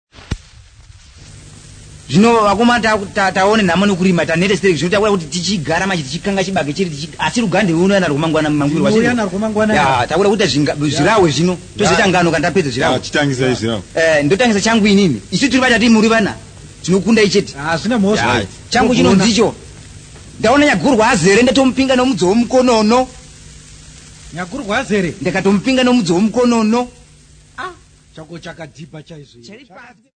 Zezuru men
Popular music--Africa
Field recordings
sound recording-musical
Indigenous music